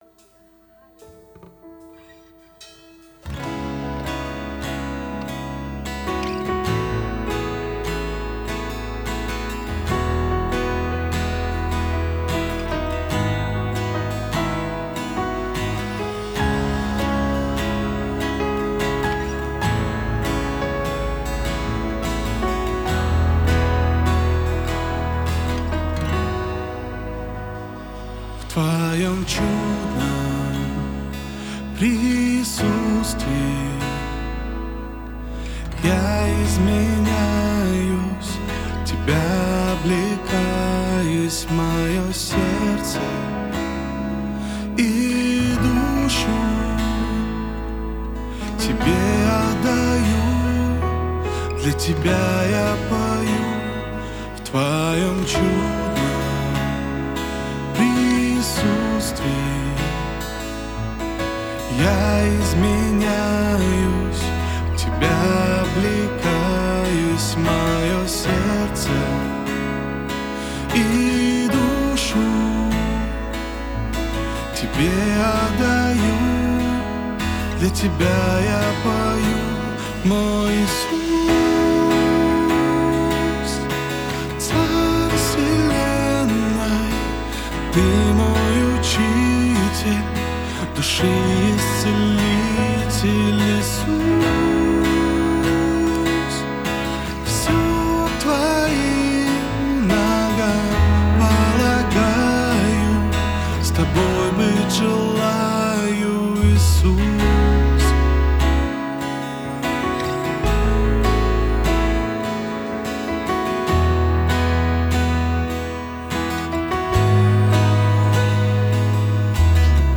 67 просмотров 163 прослушивания 4 скачивания BPM: 75